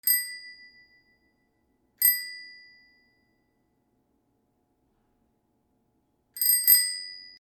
自転車 ベル 03
『リンリーン』